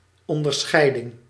Ääntäminen
IPA : [dɪˈst.ɪŋk.ʃən]